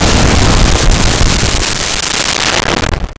Explosion 03
Explosion 03.wav